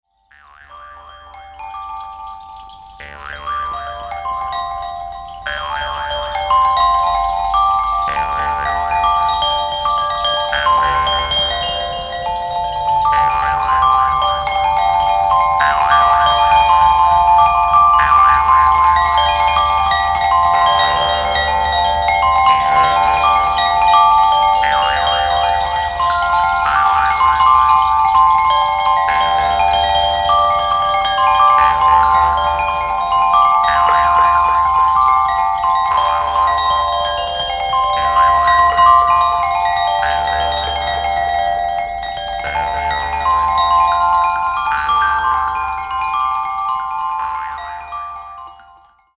即興　霊性　地中海